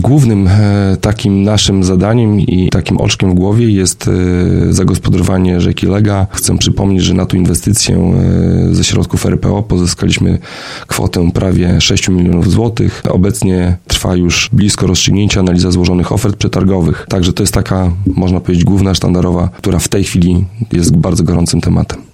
Karol Sobczak, burmistrz Olecka liczy na to, że inwestycja spełni oczekiwania mieszkańców i turystów.